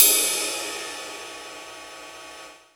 43_08_ride.wav